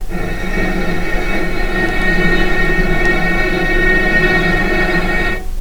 vc_sp-F#4-pp.AIF